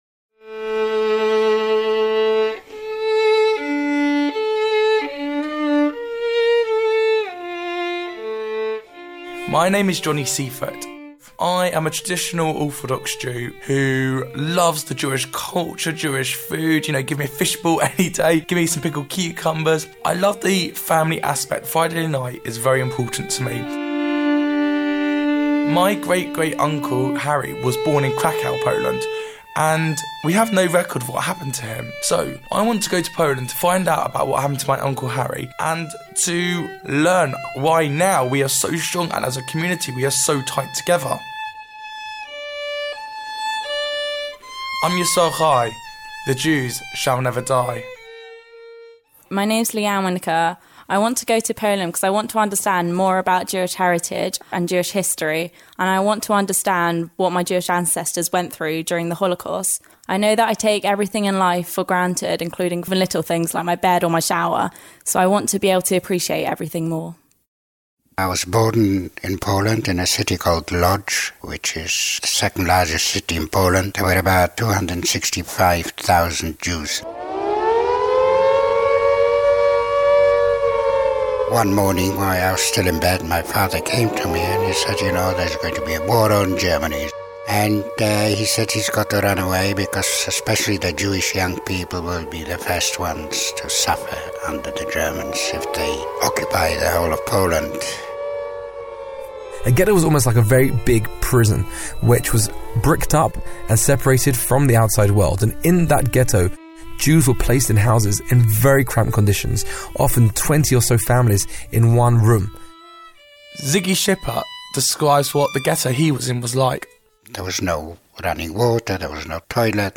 Highlights of a 30 minute documentary that I produced on my journey to Auschwitz to understand the Holocaust.